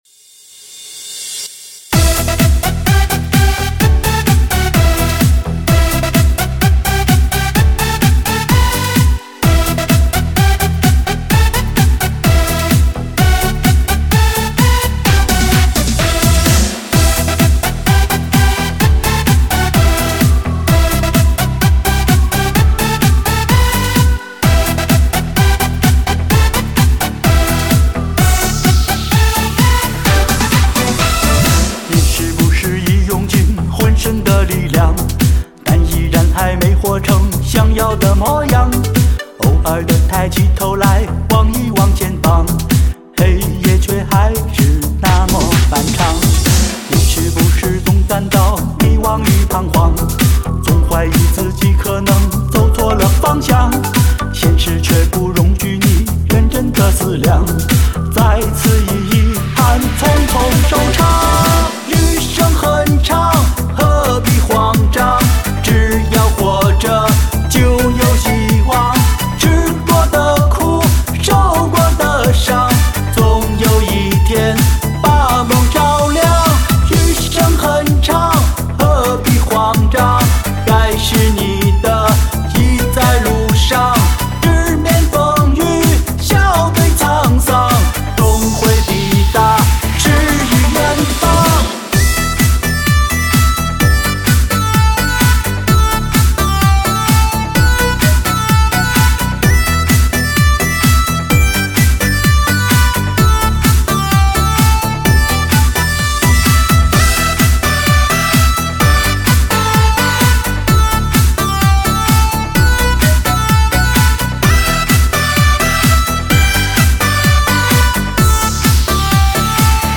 超好听中文动感DJ舞曲 车载环绕重低音 开车必备音乐